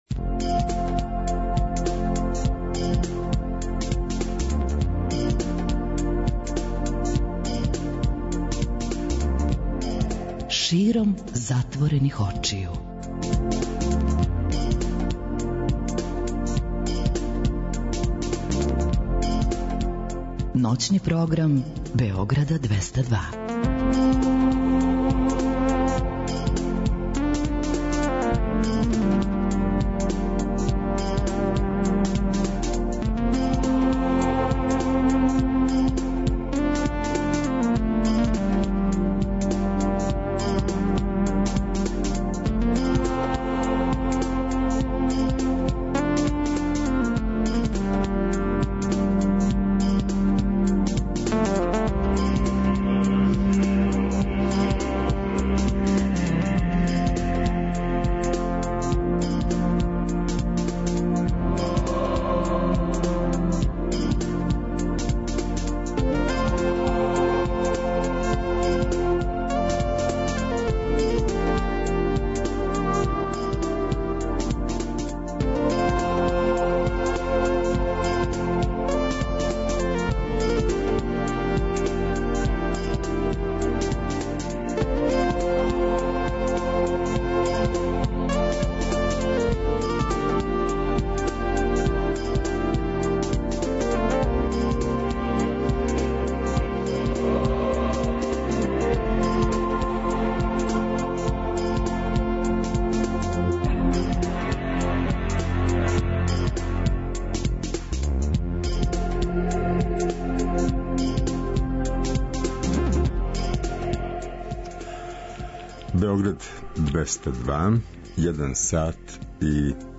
Noćni program Beograda 202.